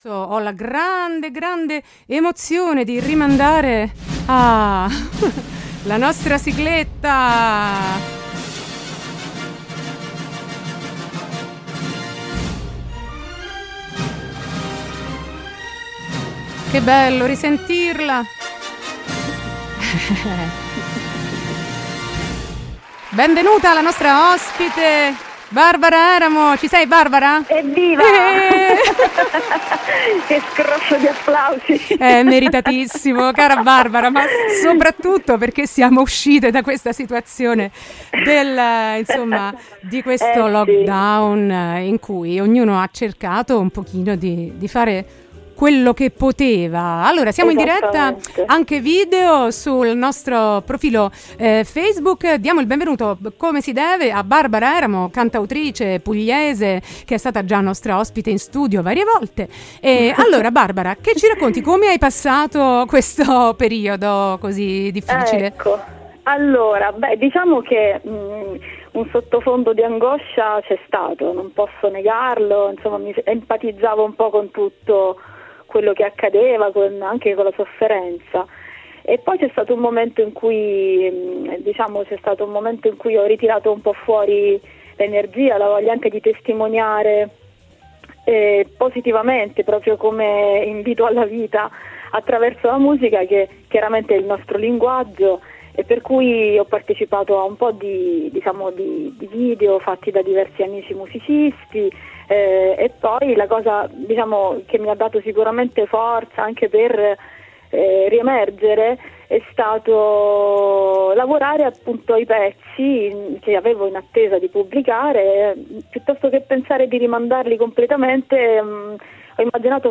Intervista
è stata ospite in collegamento telefonico